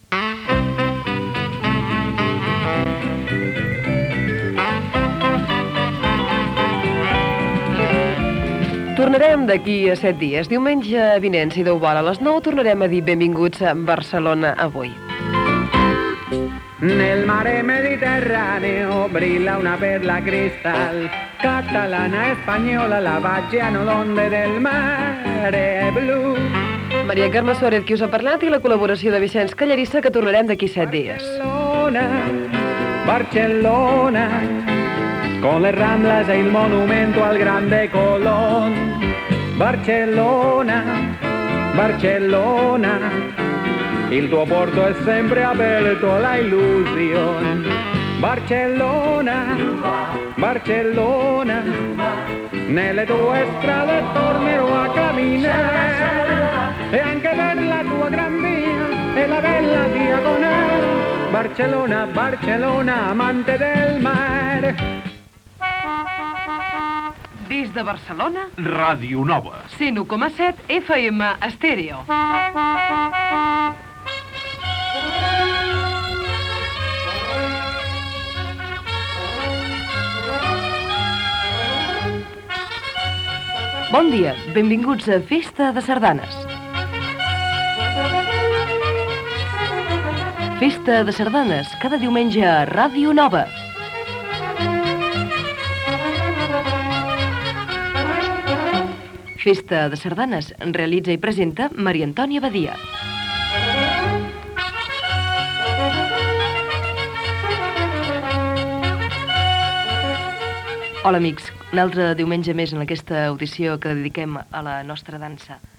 Comiat de "Barcelona avui", indicatiu i inici de "Festa de sardanes" amb la sardana Sabadell com a sintonia.
FM